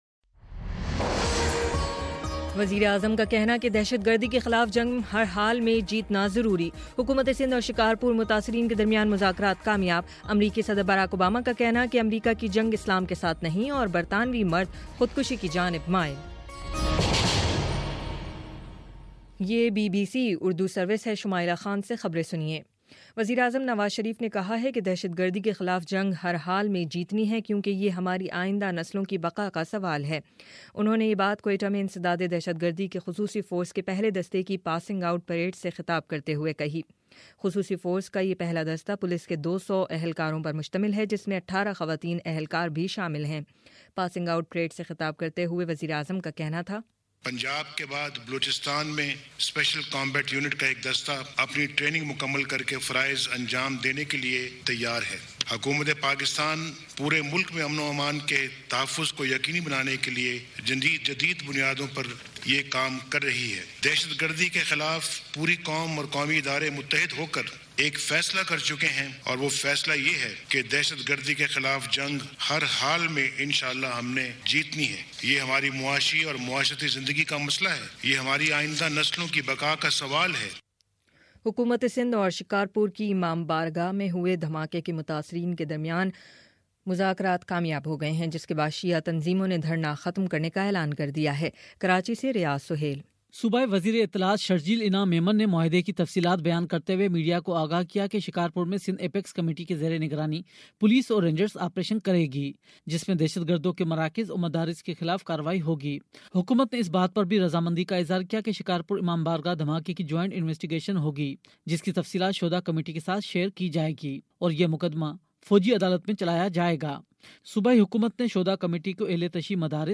فروری 19: شام چھ بجے کا نیوز بُلیٹن